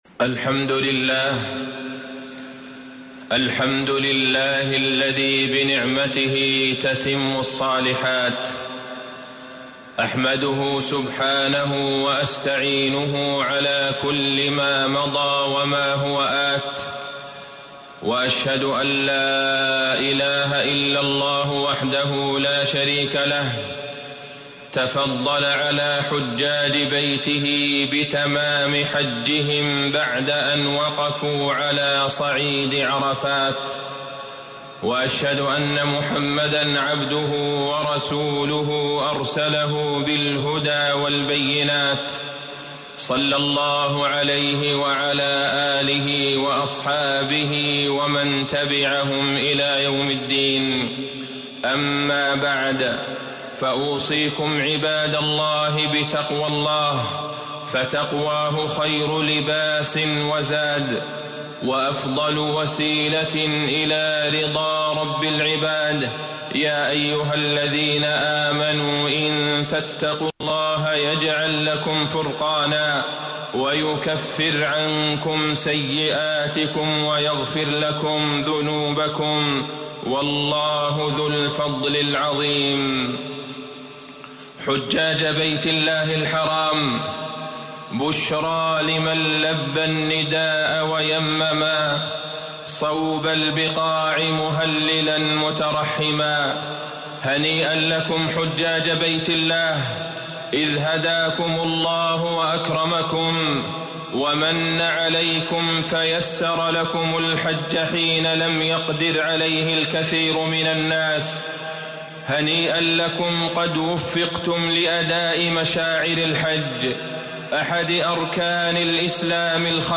تاريخ النشر ١٣ ذو الحجة ١٤٣٩ هـ المكان: المسجد النبوي الشيخ: فضيلة الشيخ د. عبدالله بن عبدالرحمن البعيجان فضيلة الشيخ د. عبدالله بن عبدالرحمن البعيجان حال المسلم بعد الحج